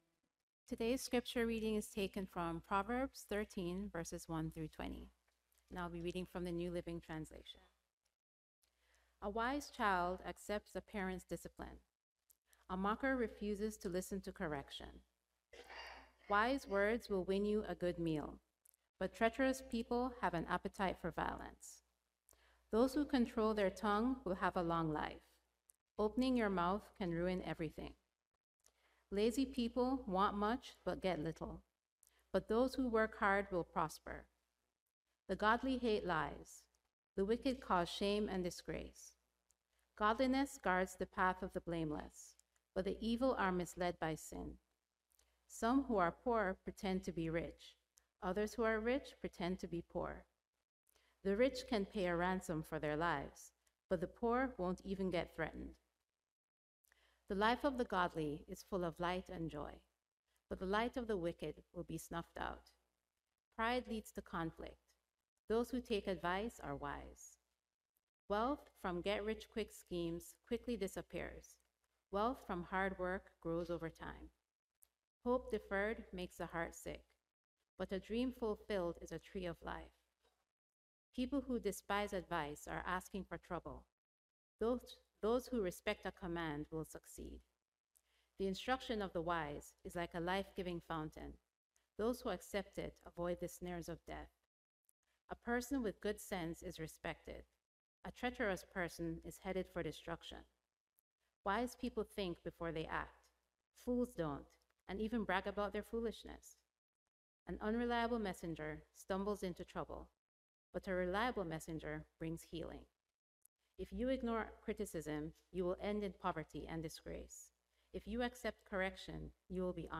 Subscribe via iTunes to our weekly Sermons